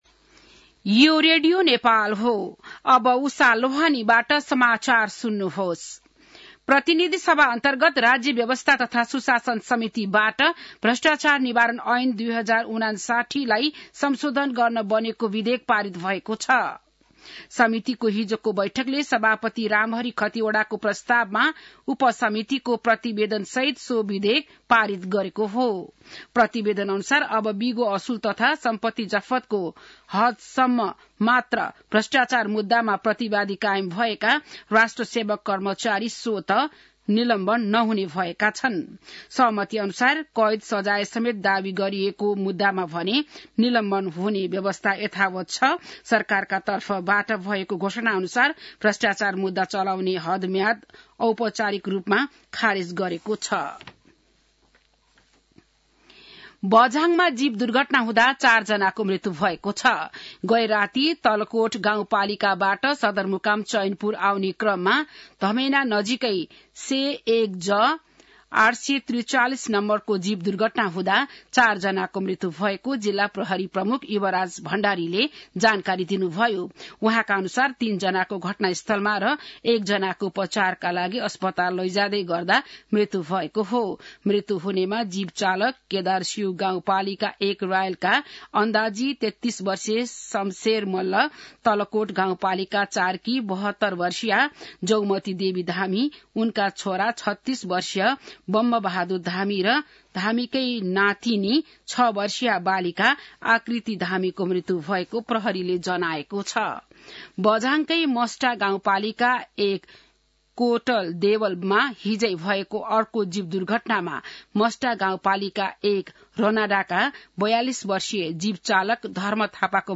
बिहान १० बजेको नेपाली समाचार : २० पुष , २०८१